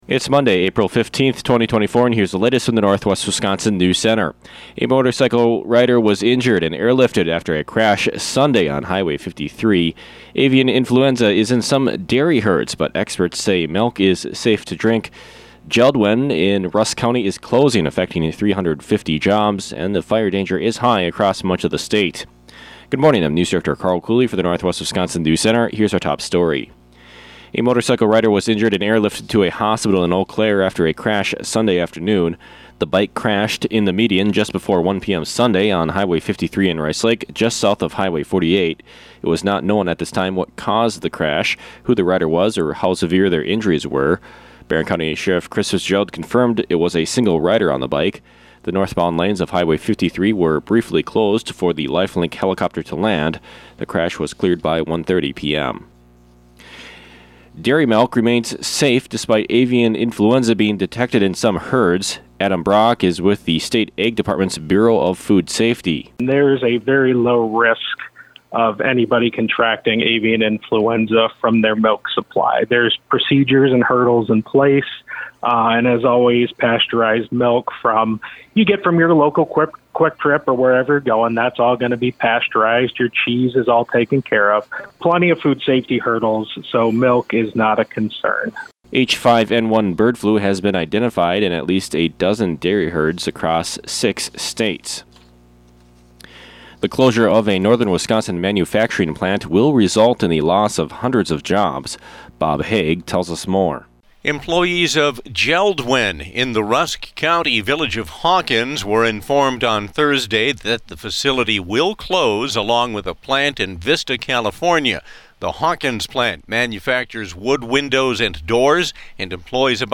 AM NEWSCAST – Monday, April 15, 2024 | Northwest Builders, Inc.